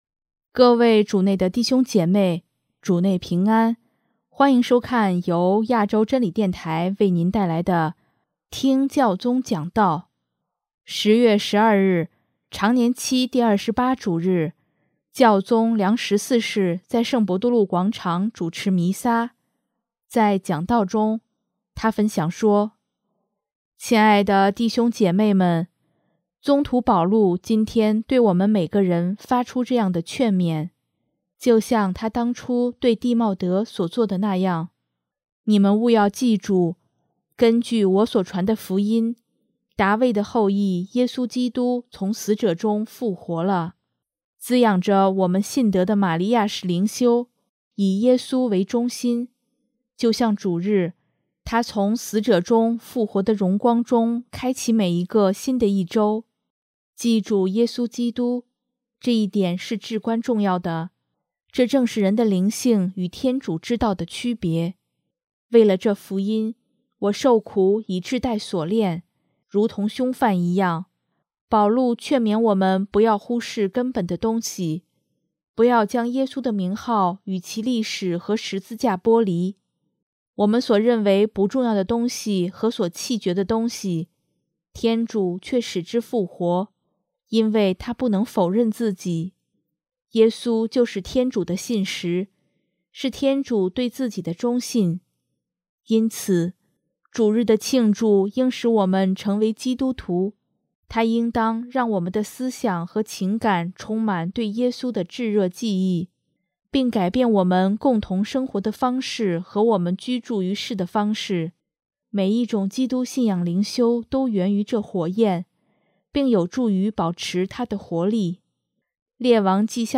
【听教宗讲道】|天主是纯粹的馈赠和全然的恩典
10月12日，常年期第二十八主日，教宗良十四世在圣伯多禄广场主持弥撒，在讲道中，他分享说：